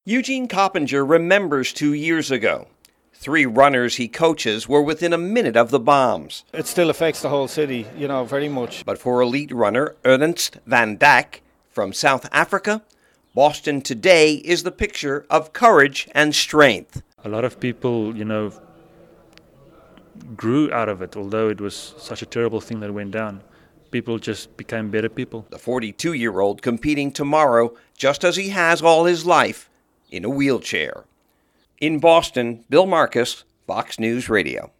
(BOSTON) APRIL 19 – 30-THOUSAND OF THE BEST RUNNERS IN THE WORLD LINE UP TOMORROW (MONDAY) FOR BOSTON’S 119TH MARATHON. FOX NEWS RADIO’S